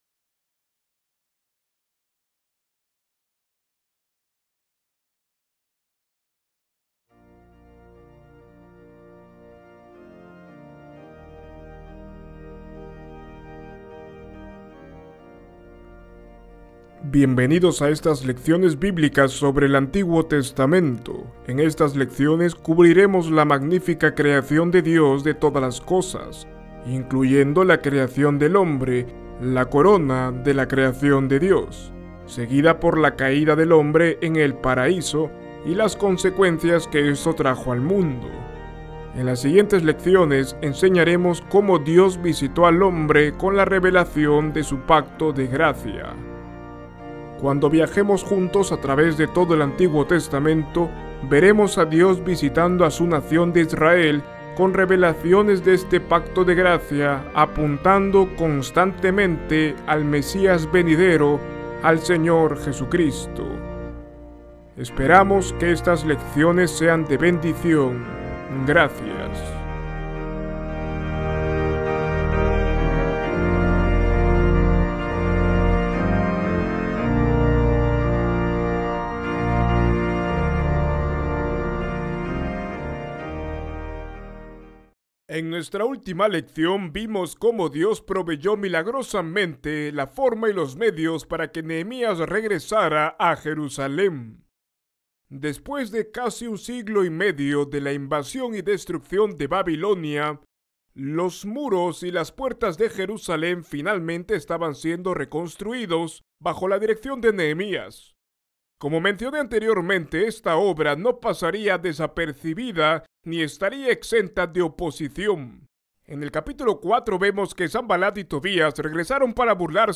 Esta lección nos lo cuenta todo.